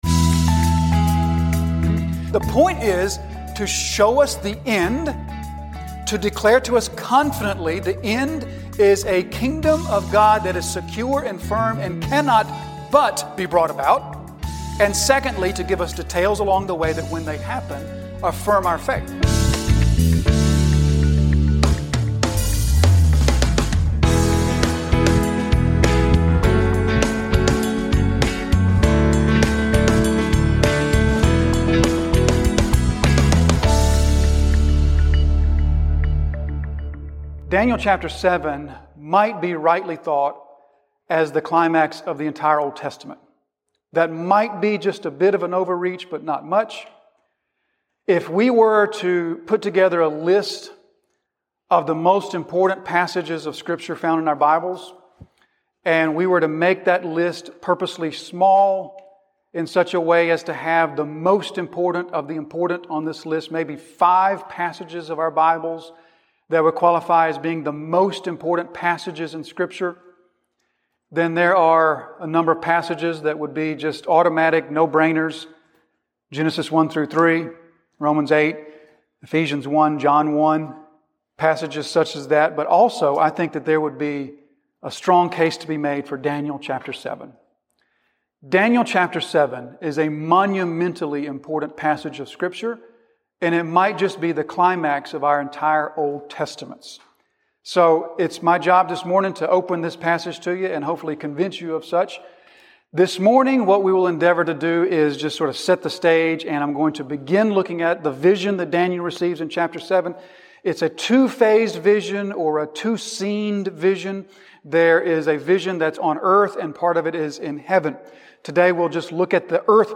An expository sermon delivered at Disciples Fellowship Church, Jonesville, NC.